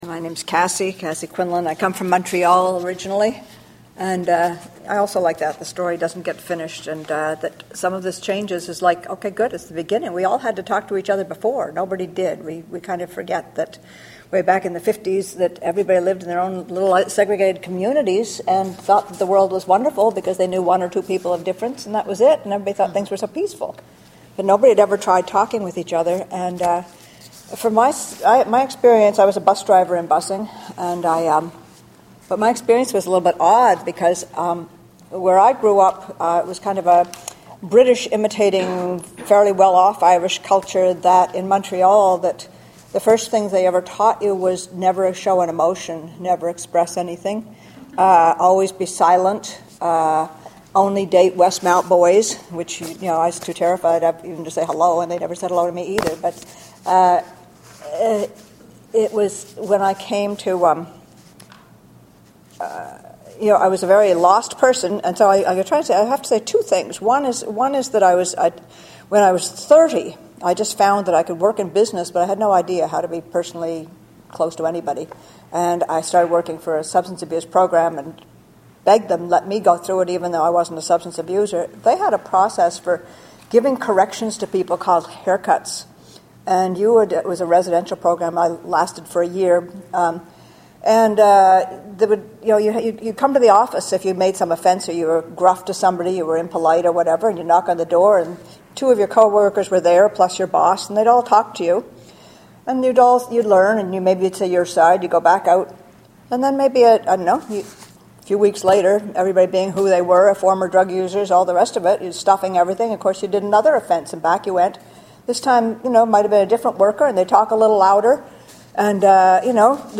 Voices from the Brighton Allston Congregational Church Story Circle